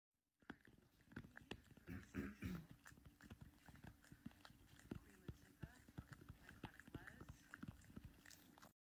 goofy looking dog chewing on sound effects free download